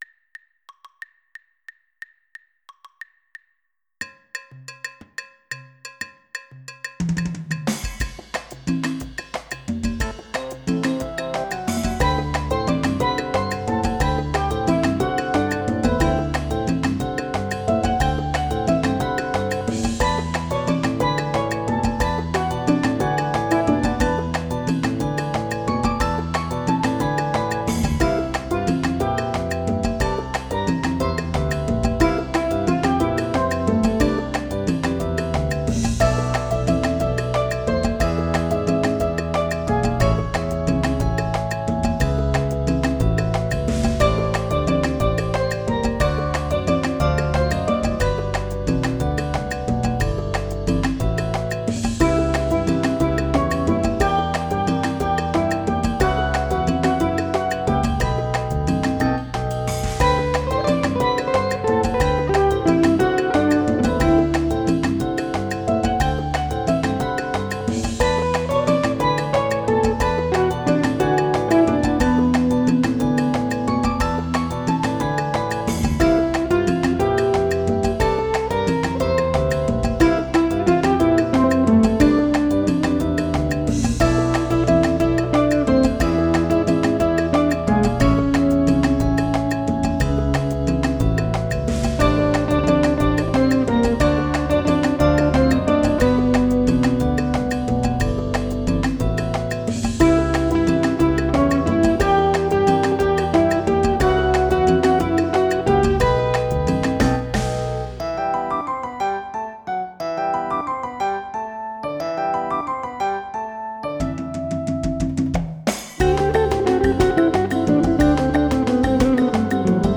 Playing my Guitars
Afro Cuban 6/8